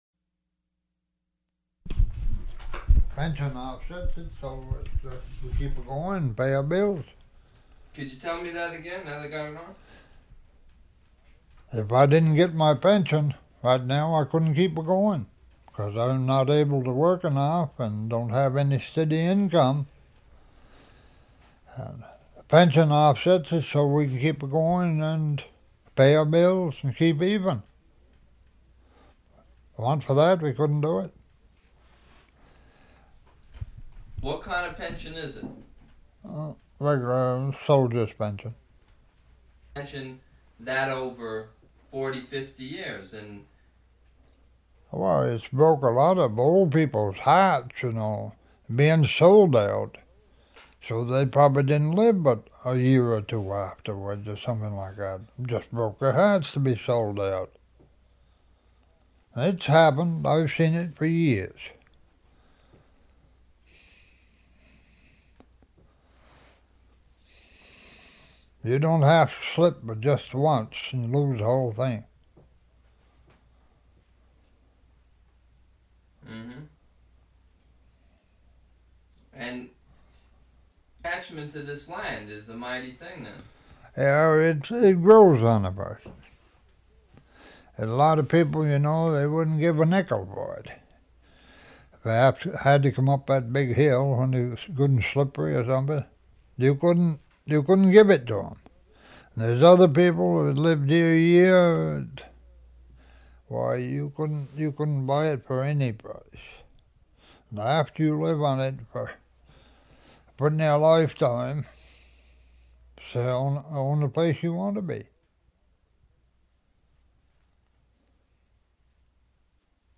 Interview
Format 1 sound tape reel (Scotch 3M 208 polyester) : analog ; 7 1/2 ips, full track, mono.